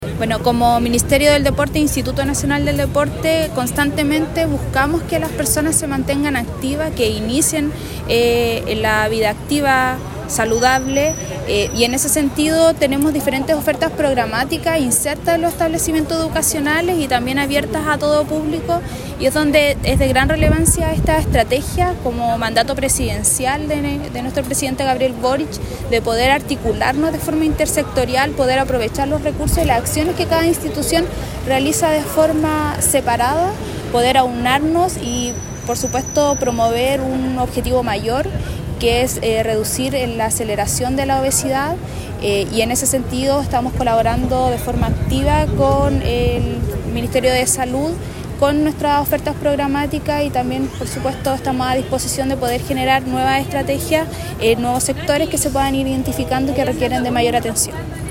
Por su parte, la Seremi del Deporte, Arling Guzmán, subrayó el rol del deporte en la estrategia: